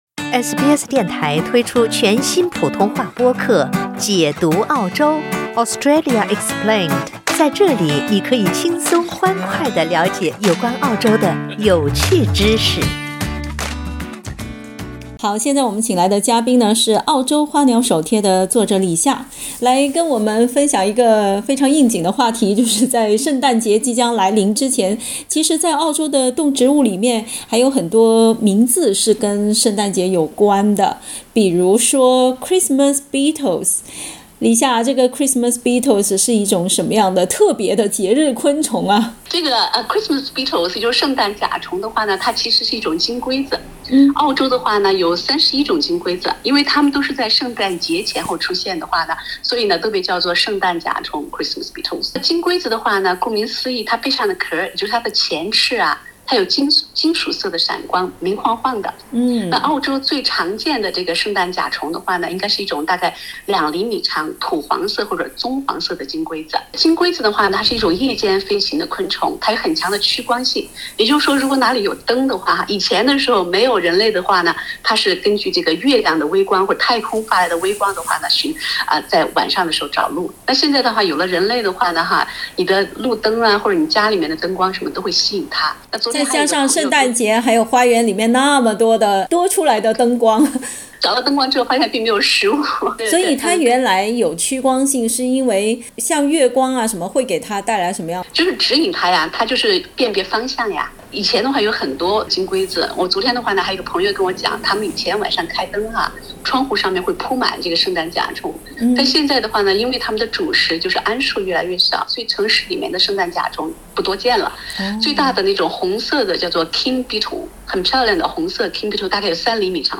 圣诞节很快就到了，除了圣诞老人会在这个时候出现，圣诞金龟子、新州圣诞树花、圣诞铃花也会赶在圣诞前后来“凑热闹”哦。（点击图片收听采访）